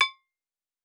Fantasy Interface Sounds
UI Tight 29.wav